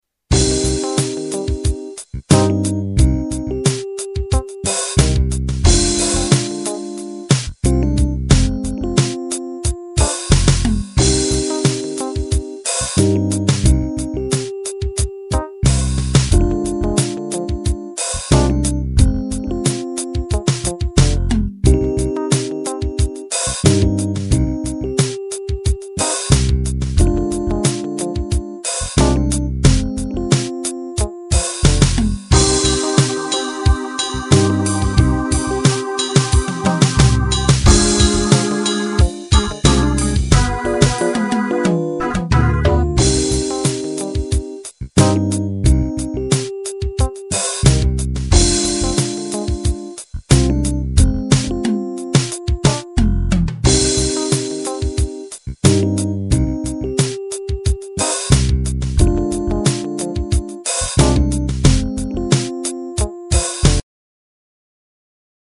back track for Got to Get up on your Feet_6.mp3